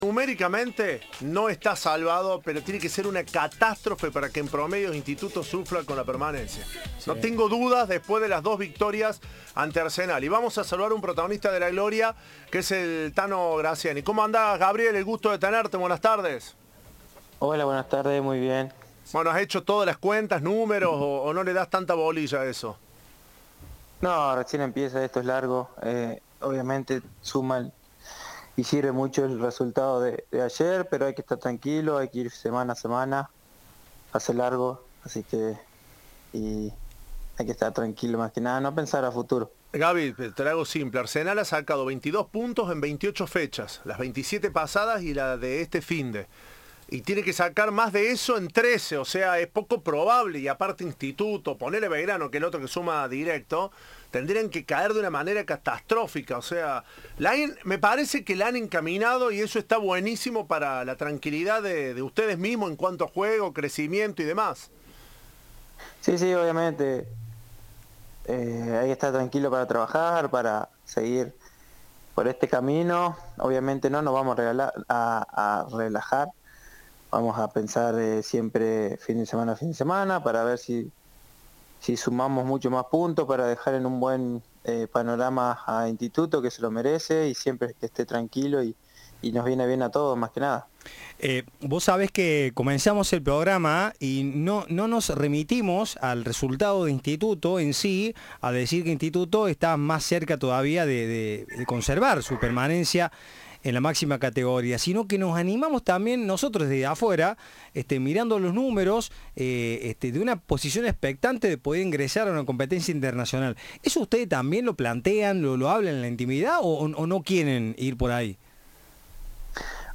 Entrevista de "Tiempo de Juego"